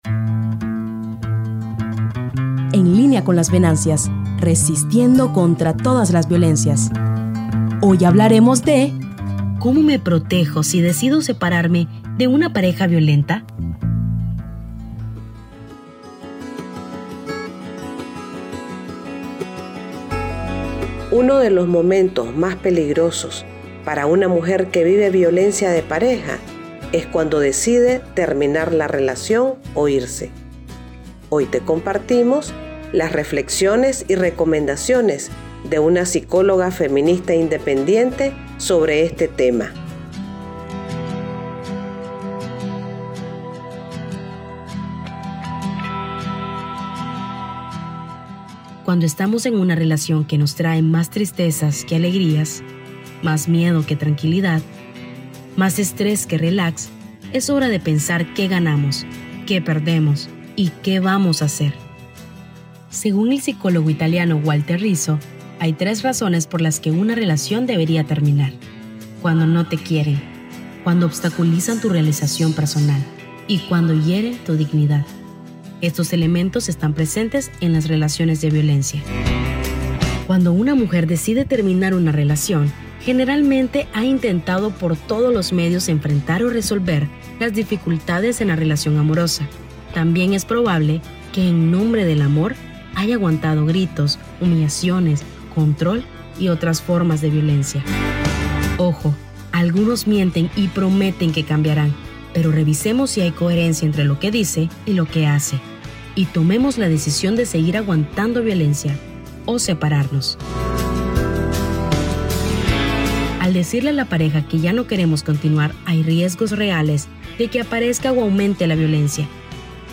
Si estás pensando en separarte o terminar la relación con un hombre que ya ha dado señales de ser violento, es importante preparar un plan para hacer efectiva la separación. Una sicóloga feminista independiente nos comparte algunas recomendaciones.
Musicalización y recursos sonoros:
Música y efectos de la biblioteca de sonidos de uso libre de Meta: